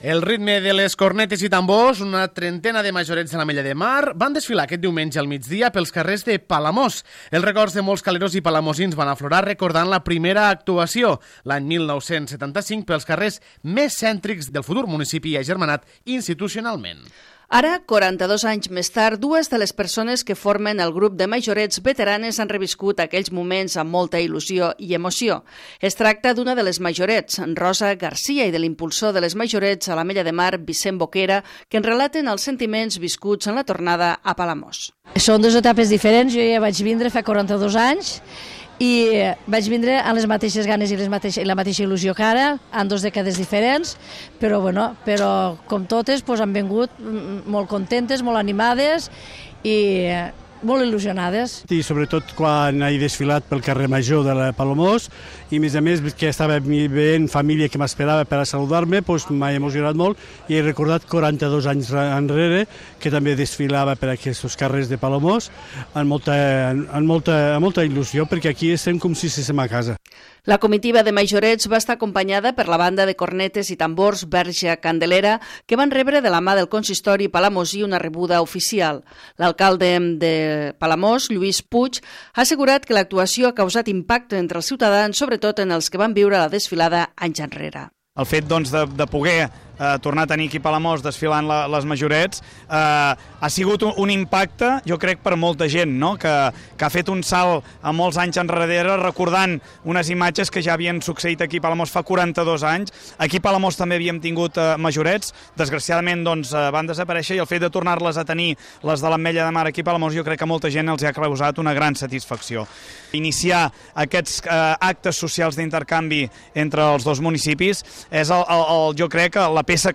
Amb el ritme de les cornetes i tambors, una trentena de Majorettes de l'Ametlla de Mar van desfilar, aquest diumenge al migdia, pels carrers de Palamós.